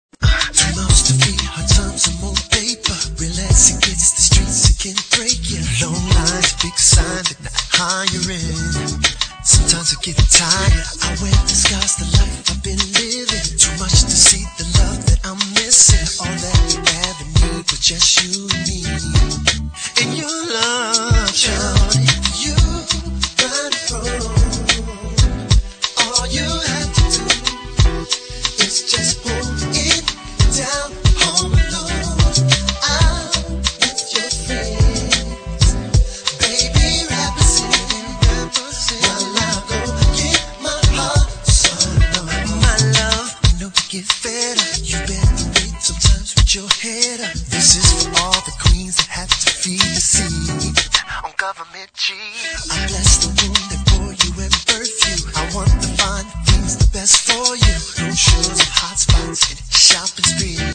Soul/R&B/Jazz